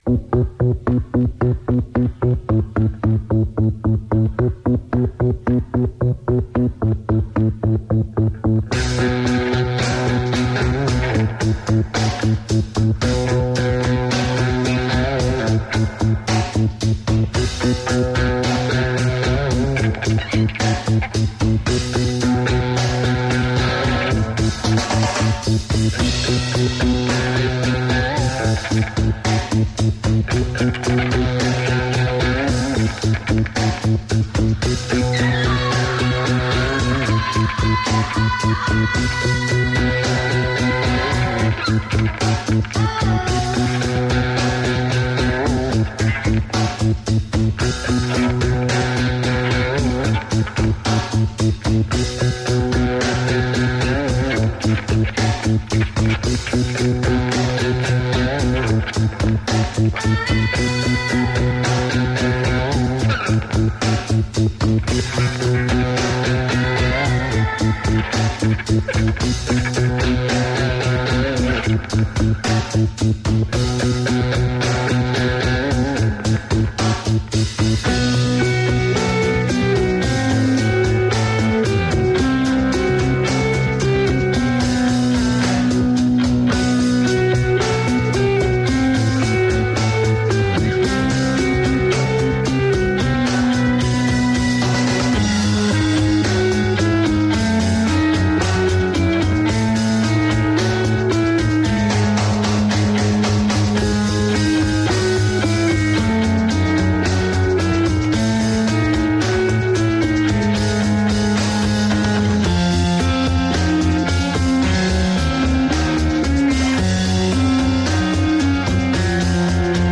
alternative rock band
vocals, keys, guitar/rhythm guitar
drums and lead guitar/keys/vocals